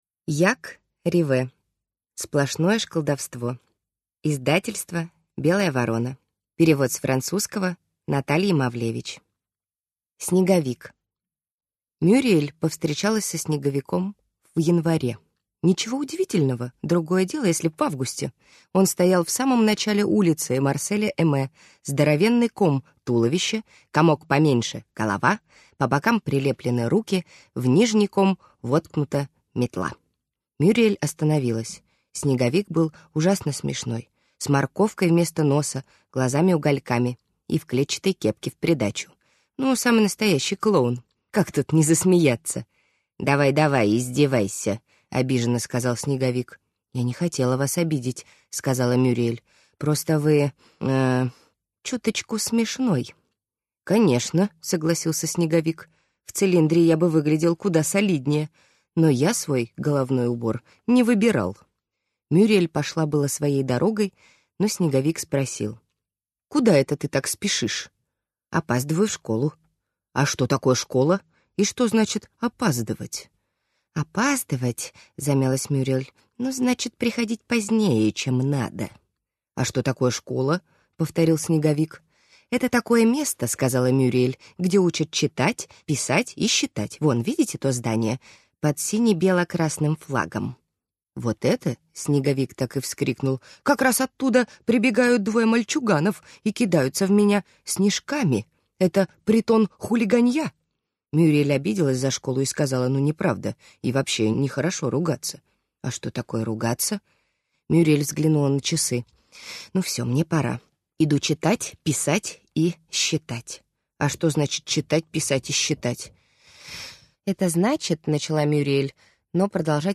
Аудиокнига Сплошное школдовство | Библиотека аудиокниг